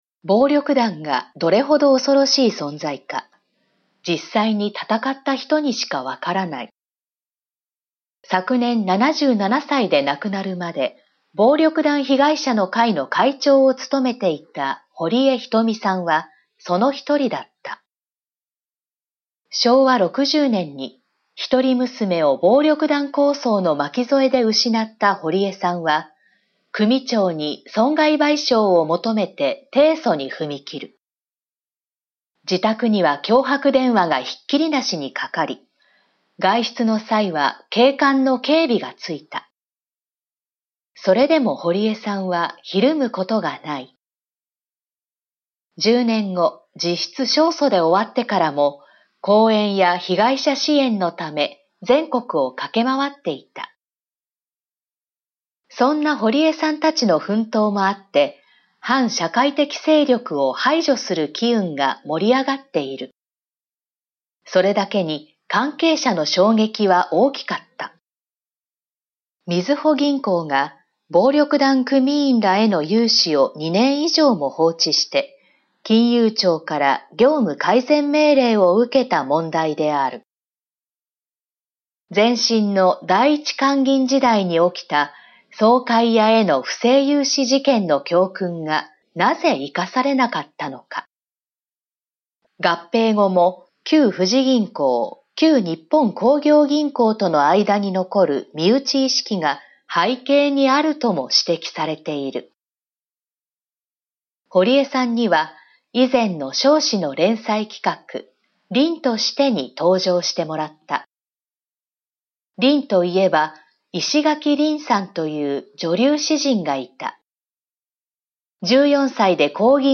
全国240名の登録がある局アナ経験者がお届けする番組「JKNTV」
産経新聞1面のコラム「産経抄」を、局アナnetメンバーが毎日音読してお届けします。